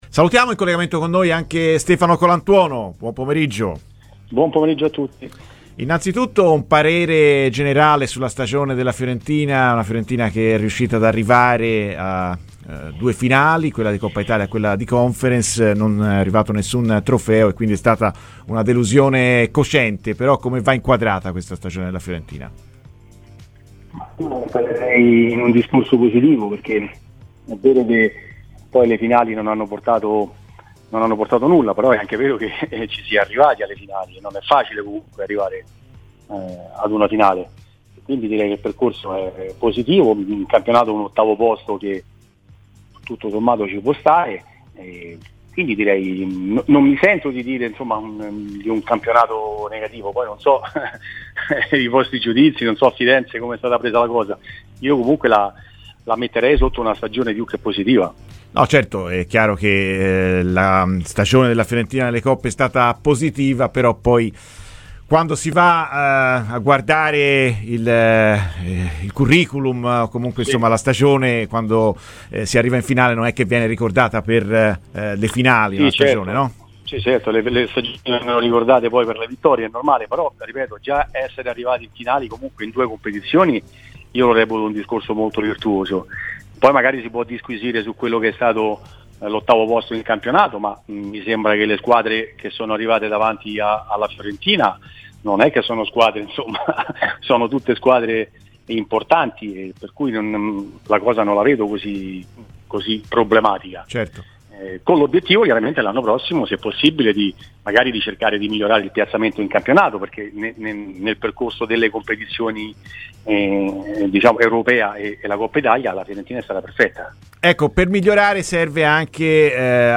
Stefano Colantuono, ex allenatore e attuale responsabile del settore giovanile della Salernitana, ha parlato oggi a 'Viola Amore Mio' in onda su Radio Firenzeviola.